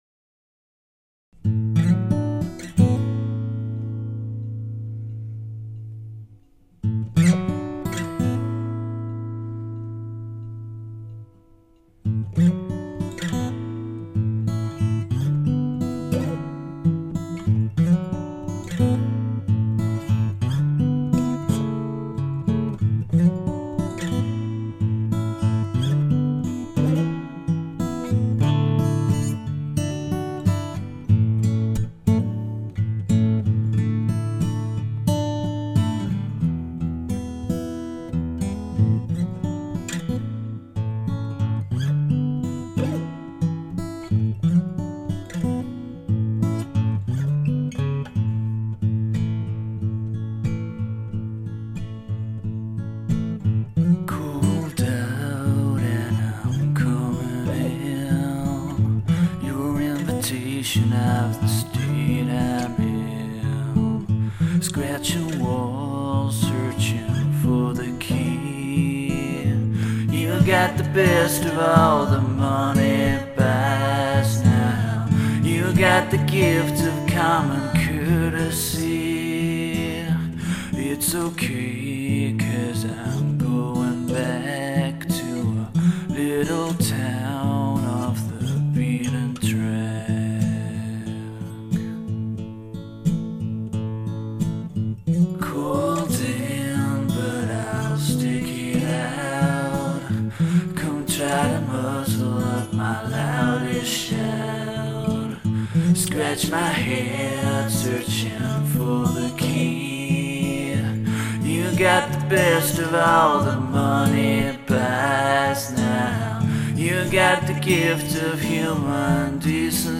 Wonderfully smooth song